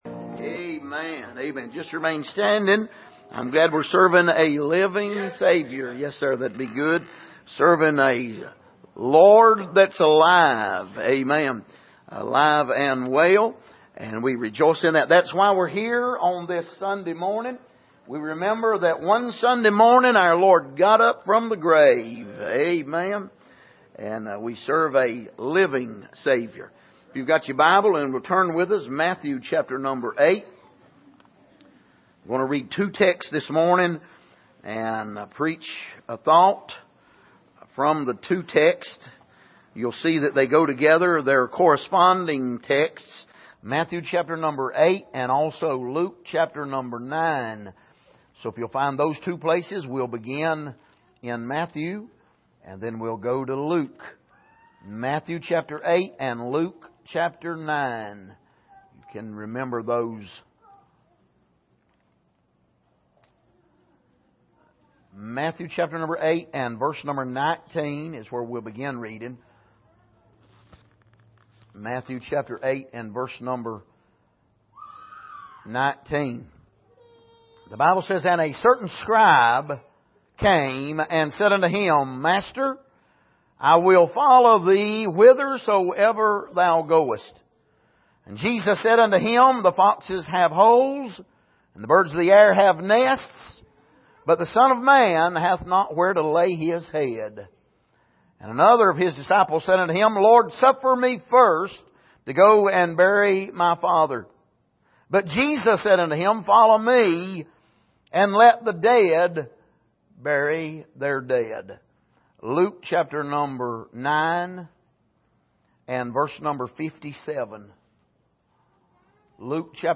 Passage: Matthew 8:19-22 Service: Sunday Morning